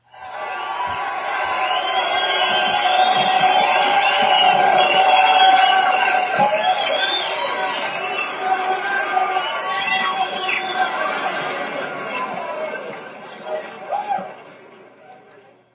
Now, take your bow. Applause
applause.mp3